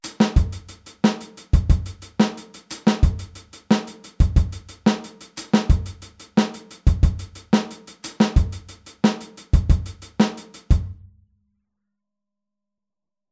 Aufteilung linke und rechte Hand auf HiHat und Snare
Groove22-16tel.mp3